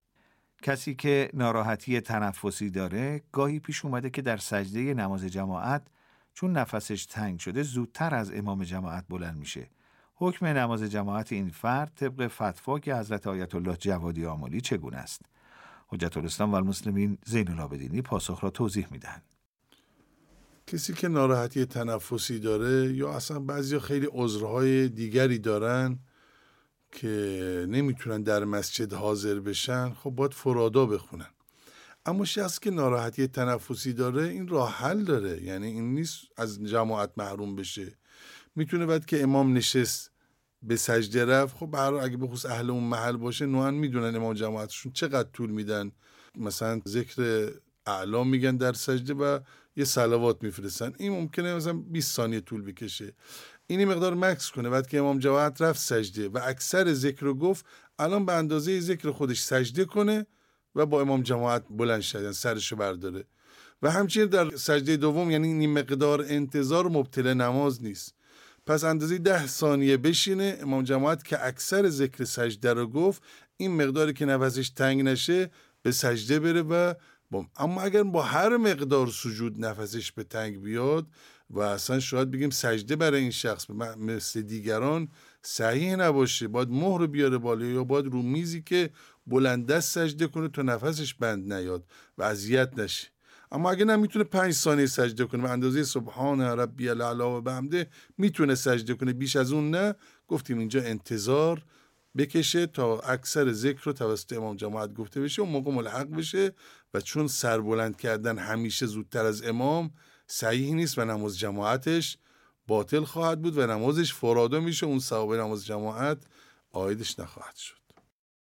پاسخ نماینده دفتر حضرت آیت الله العظمی جوادی آملی